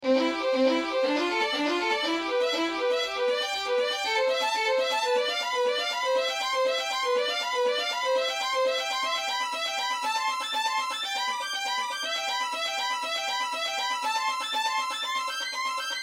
The next version is the same part, with the same parameters, only with the rising data, and the difference in tone and build for me is striking.
thrill-euphoria-3-NA.mp3